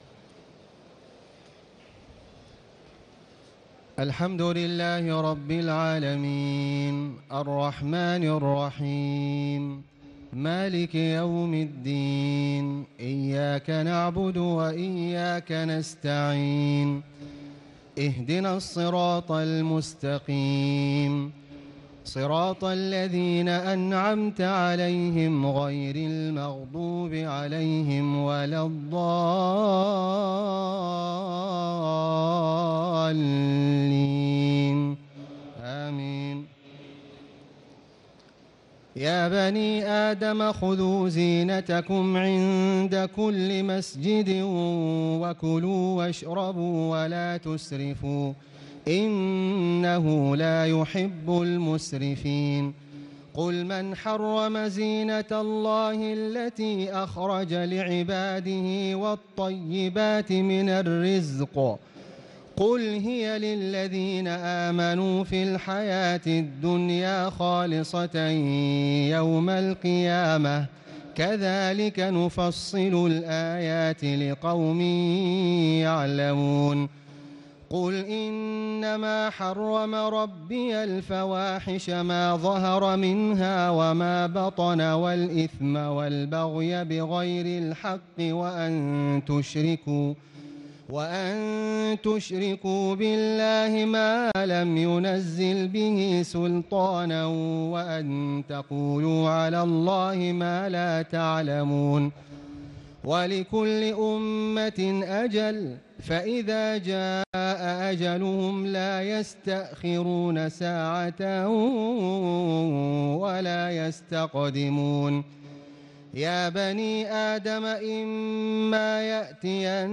تهجد ليلة 28 رمضان 1438هـ من سورة الأعراف (31-93) Tahajjud 28 st night Ramadan 1438H from Surah Al-A’raf > تراويح الحرم المكي عام 1438 🕋 > التراويح - تلاوات الحرمين